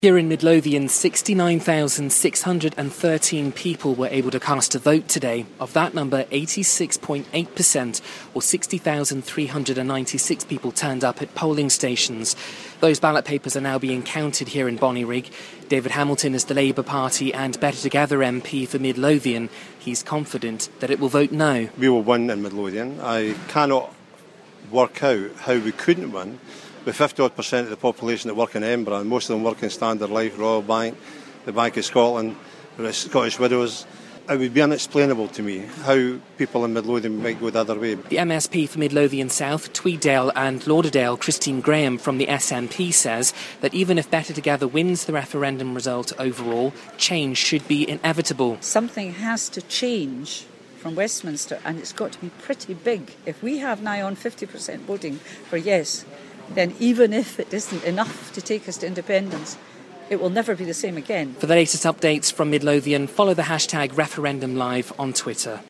Those ballot papers are now being counted here in Bonnyrigg. David Hamilton is the Labour Party and Better Together MP for Midlothian. He's confident Midlothian will vote No.